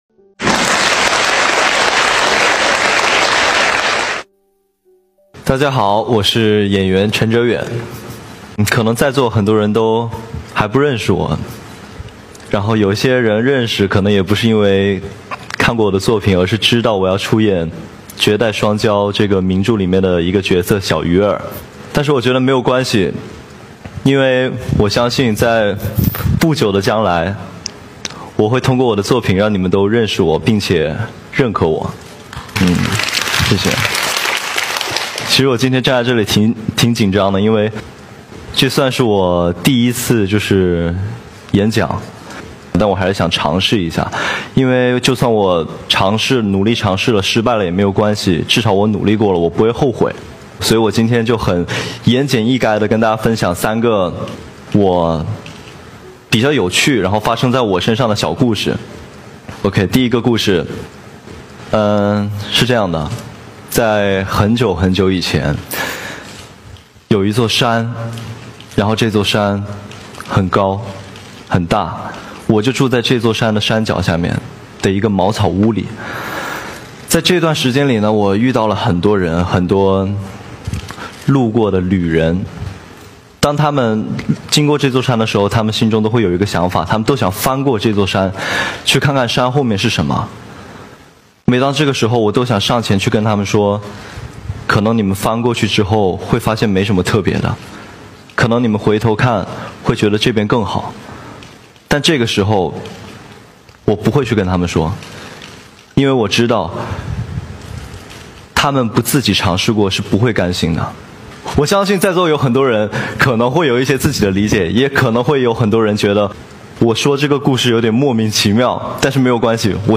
14.09.2019 สุนทรพจน์ของเฉินเจ๋อหย่วนบนเวที งาน Youth Power sound effects free download